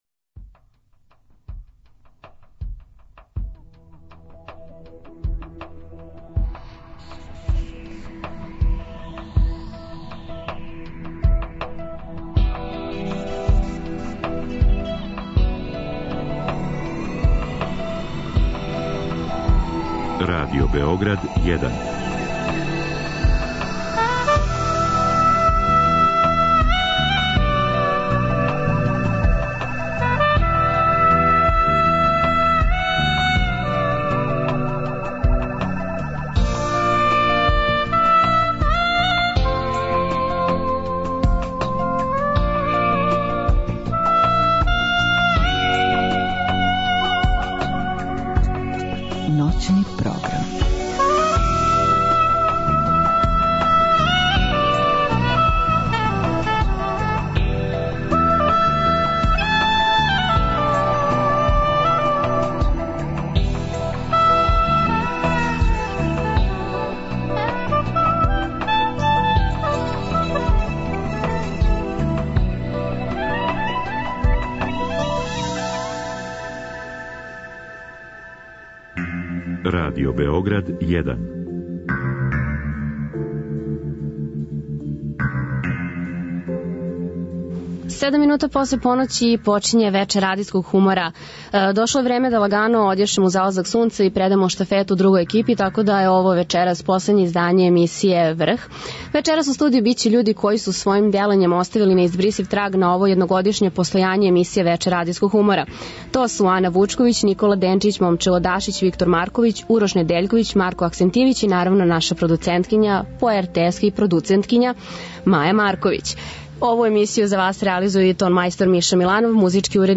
Сваке ноћи, од поноћи до четири ујутру, са слушаоцима ће бити водитељи и гости у студију